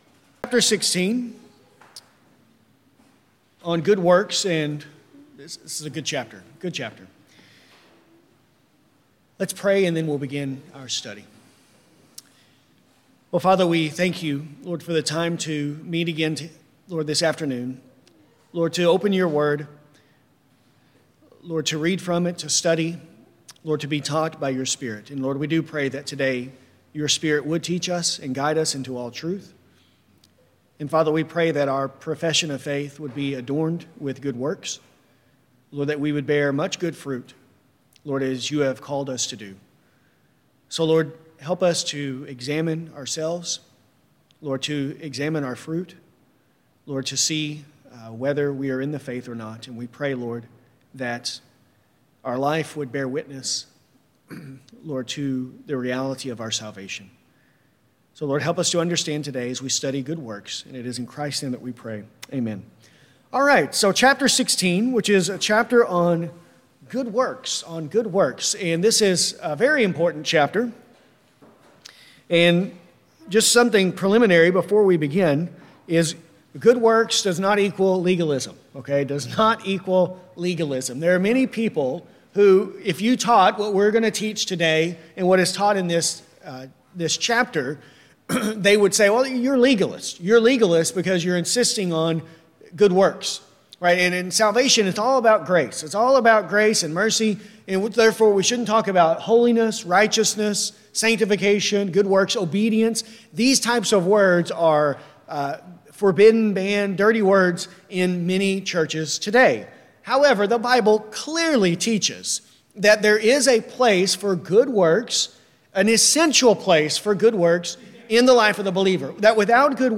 Good works are only such as God hath commanded in his Holy Word, and not such as without the warrant thereof are devised by men out of blind zeal, or upon any pretense of good intentions. This lesson covers Paragraphs 16.1-16.2. To follow along while listening, use the link below to view a copy of the confession.